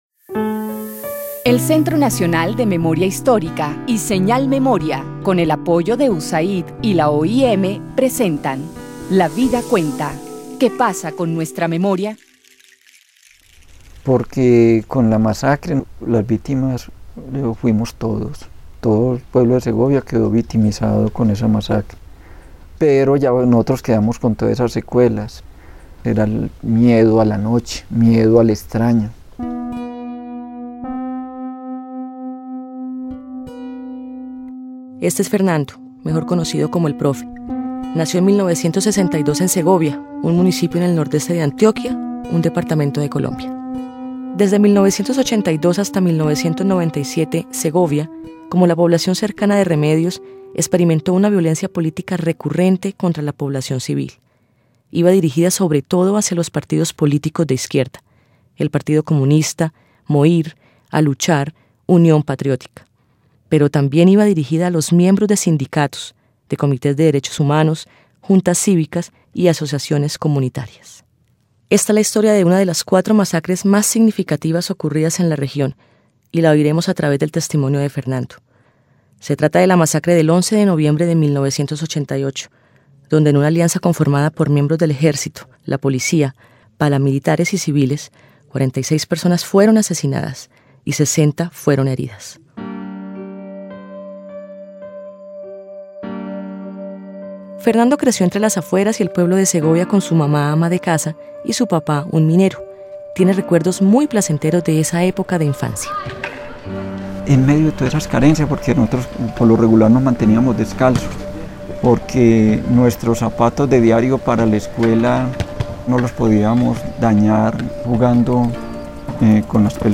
(tomado de la fuente) Audiencia (dcterms:audience) General Descripción (dcterms:description) Serie radial basada en el informe ¡Basta ya! Colombia: memorias de guerra y dignidad.